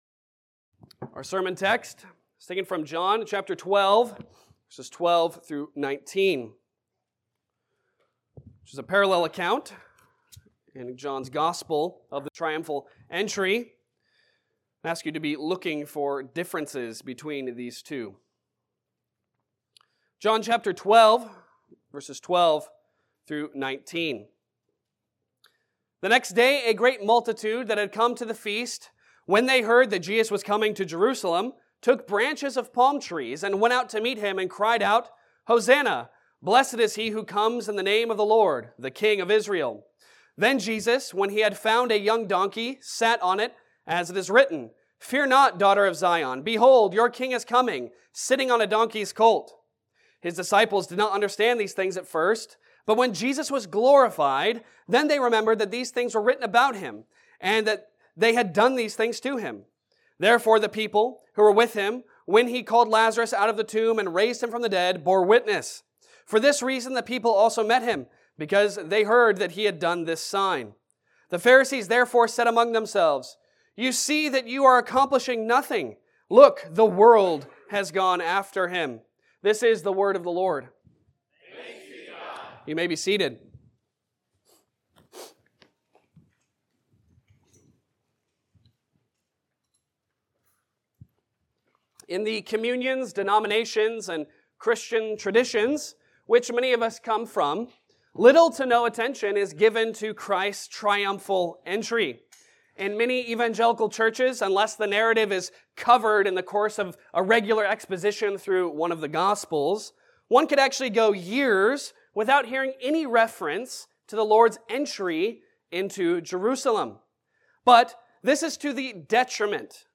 Passage: John 12:12-19 Service Type: Sunday Sermon